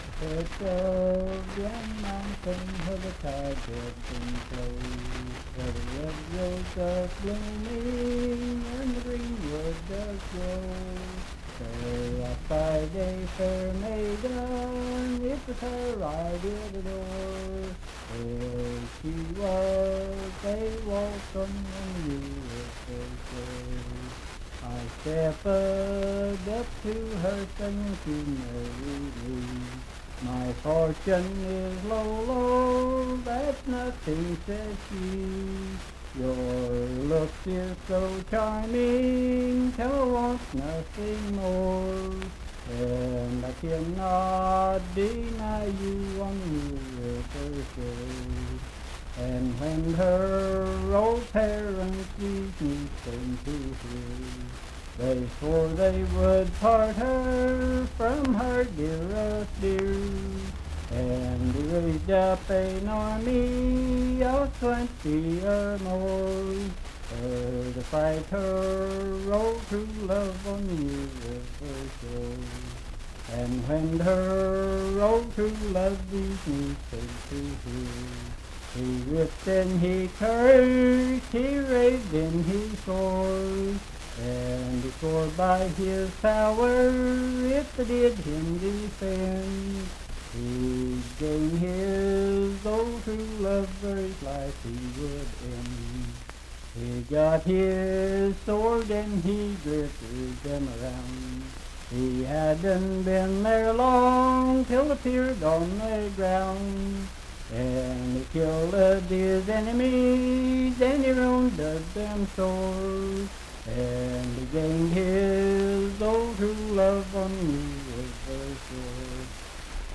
Unaccompanied vocal music
in Riverton, W.V.
Verse-refrain 5(4).
Voice (sung)